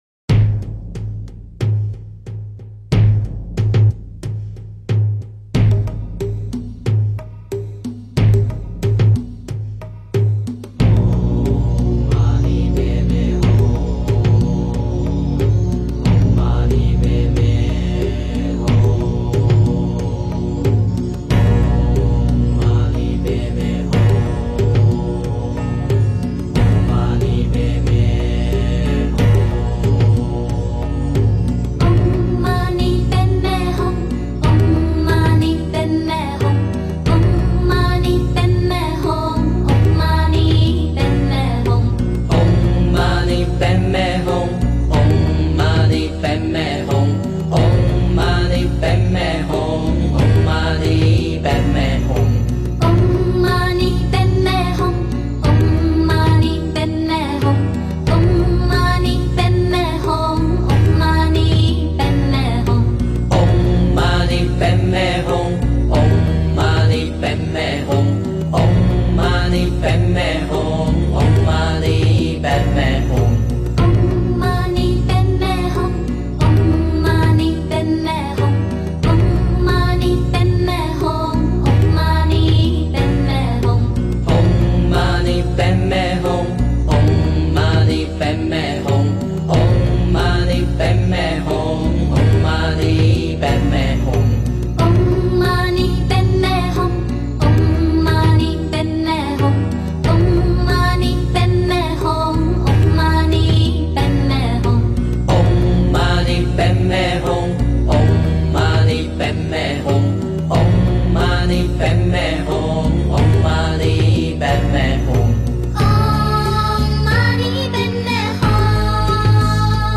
六字大明咒 - 诵经 - 云佛论坛